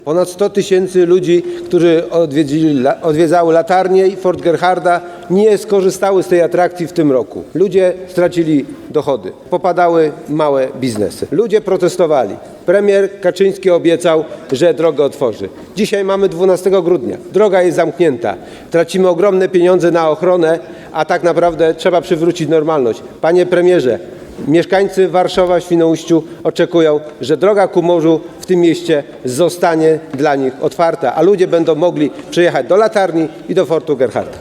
Apel do nowego premiera z trybuny sejmowej. Chodzi o drogę dla turystów